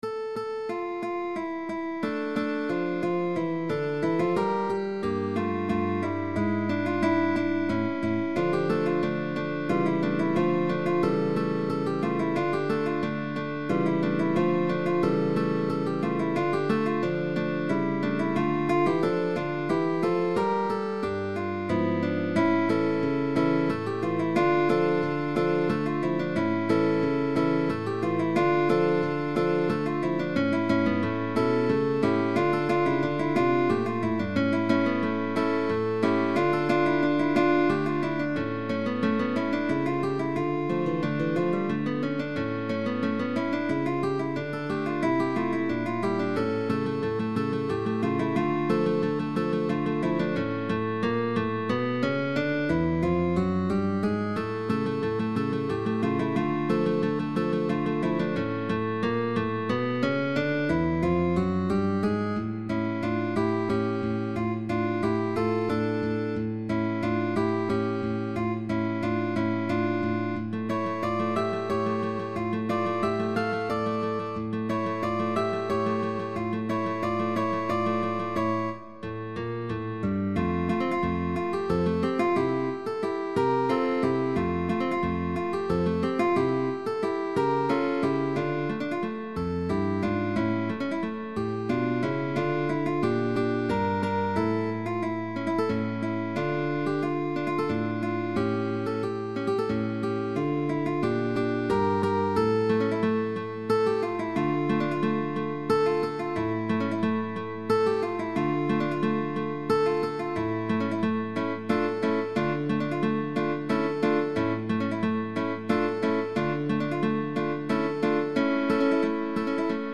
Guitar trio sheetmusic.
Arpeggios for three and four fingers. Thumb Melody bass.
GUITAR TRIO